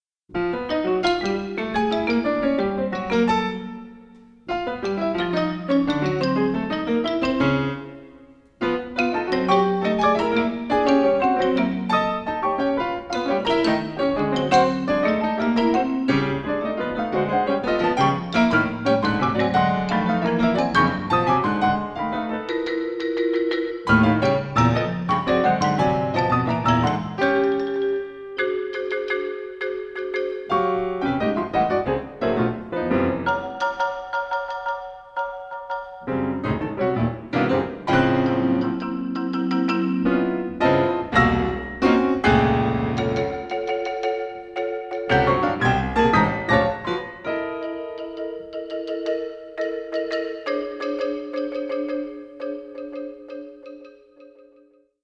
piano
percussion